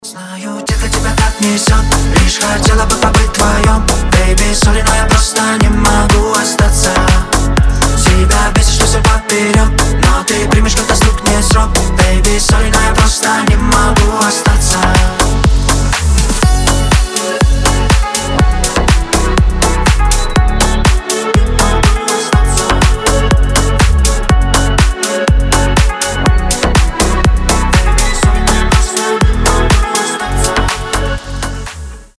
• Качество: 320, Stereo
Club House
ремиксы